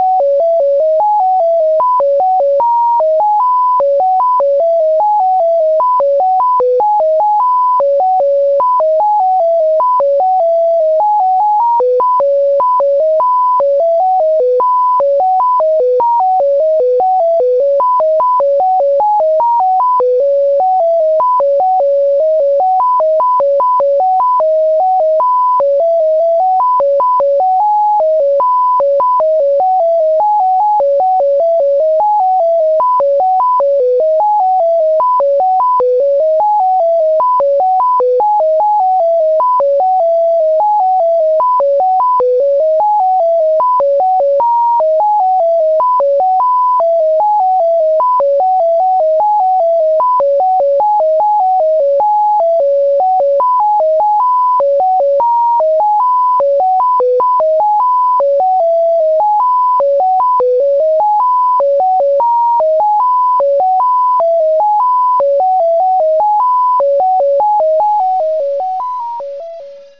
the second avoids the flat note by using the same note as the lowest one an octave higher.